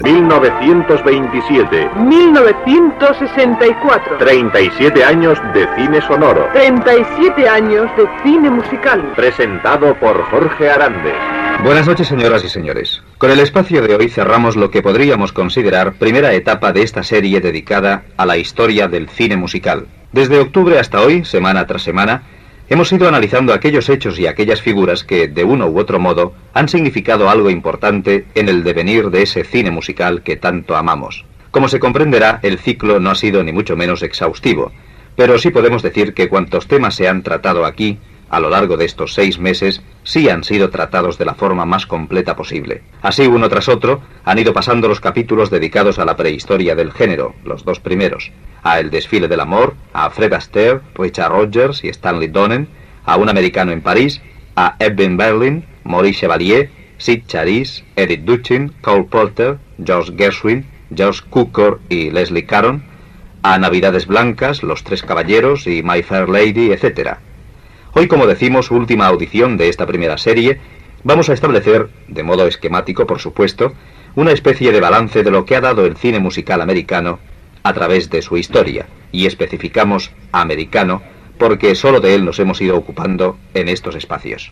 Careta i presentació de l'últim programa dedicat a 37 anys de cinema musical.
Musical